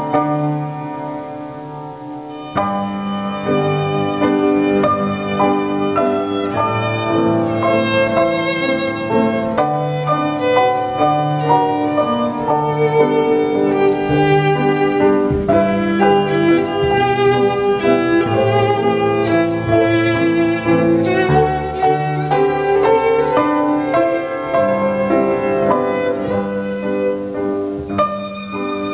- musique viennoise: